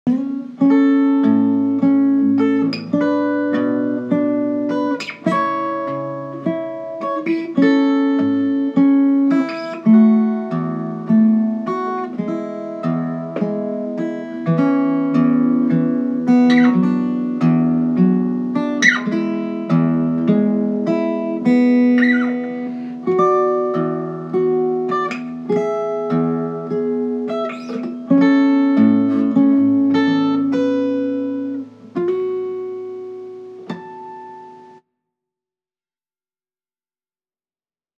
音乐疗法——第一期第四首 安慰，甚至是治愈，用简单而温暖的吉他音乐，让人们感受到亲切和温馨，找到共鸣和安慰 Comfort, even healing, using simple and warm guitar music to make people feel kind and warm, finding resonance and comfort 快来听听这首音乐，与你产生了什么样的共鸣！